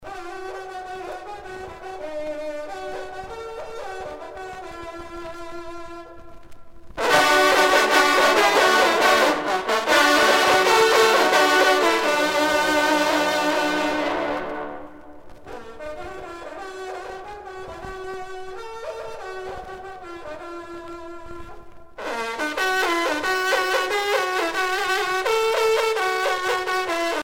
trompe - fanfare - personnalités
circonstance : vénerie
Pièce musicale éditée